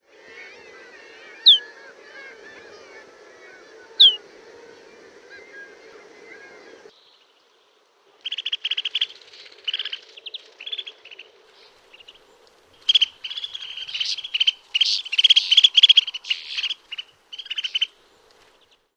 Pulmusen kutsuääni